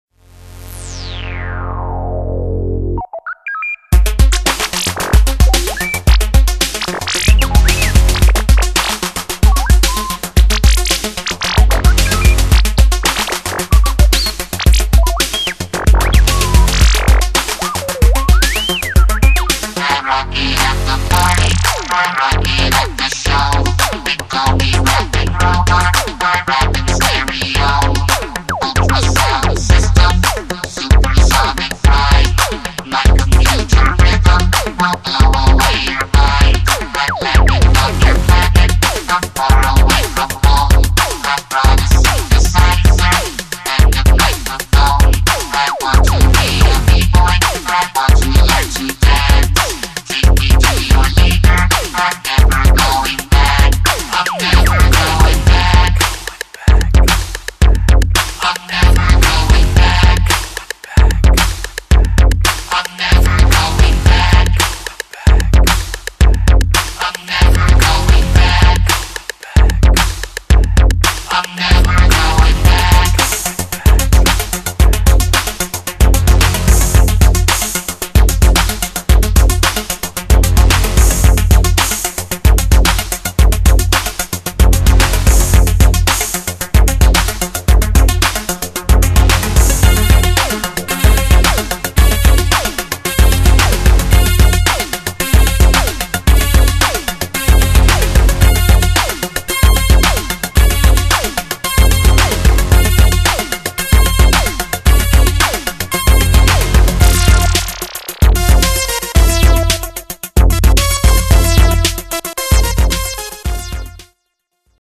Electrofunk, Bass & Technobreaks VINYLs